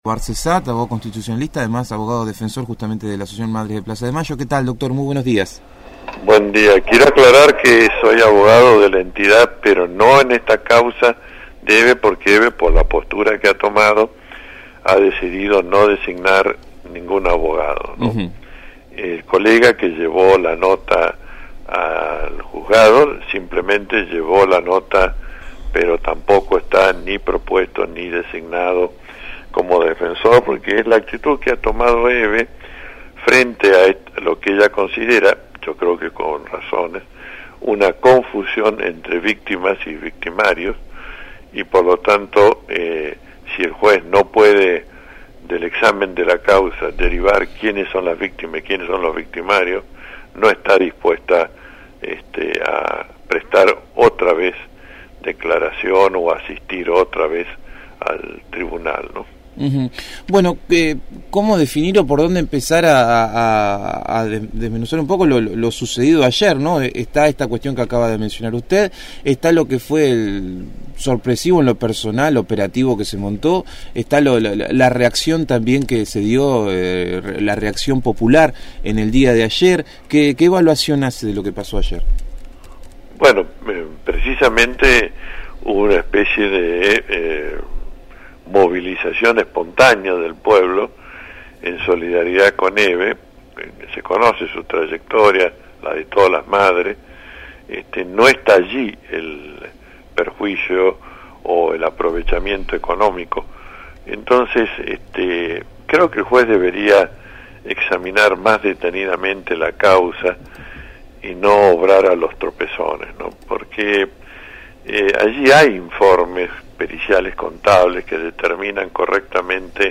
dialogó con el equipo de «El hormiguero» sobre la situación legal de la presidenta del organismo de derechos humanos. «El Hormiguero»/ FM 107.5 Conducción